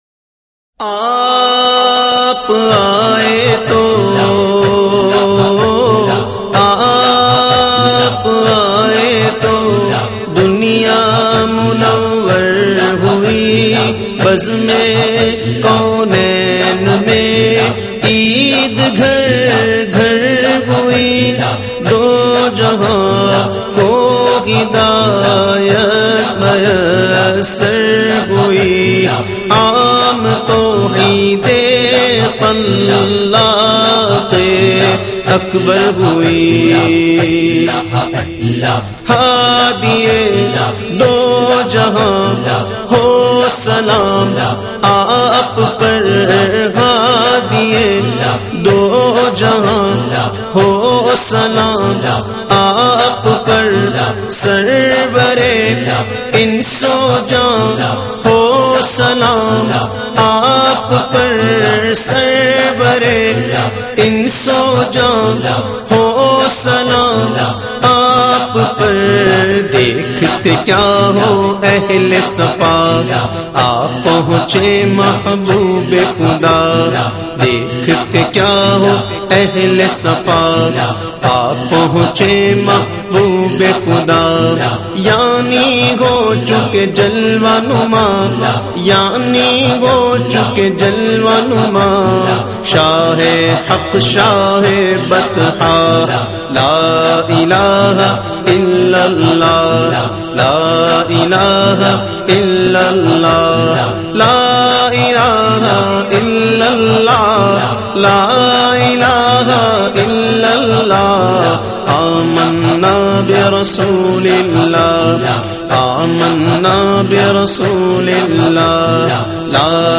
Naat Khawan